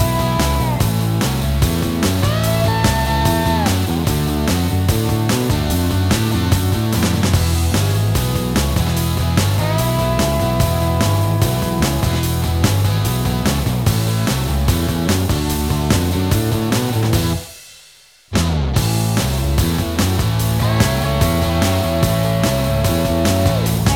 Minus Solo Guitar Soft Rock 2:50 Buy £1.50